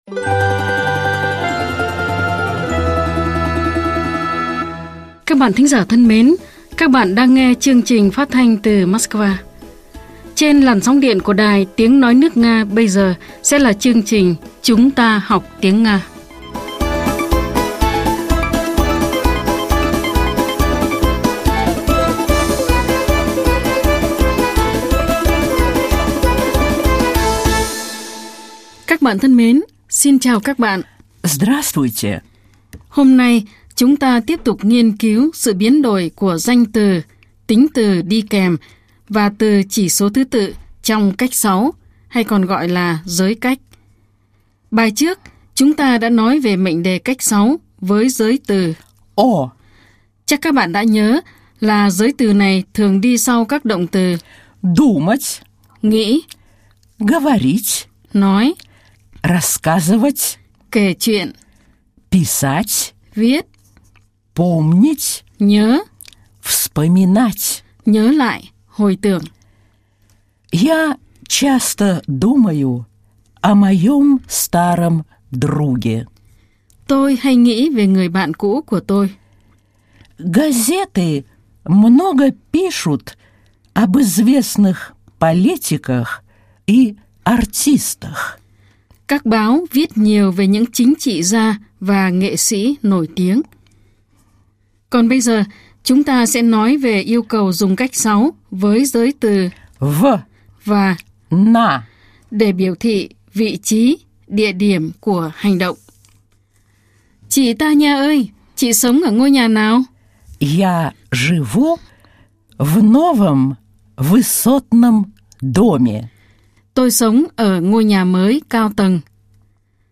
Bài 57 – Bài giảng tiếng Nga
Nguồn: Chuyên mục “Chúng ta học tiếng Nga” đài phát thanh  Sputnik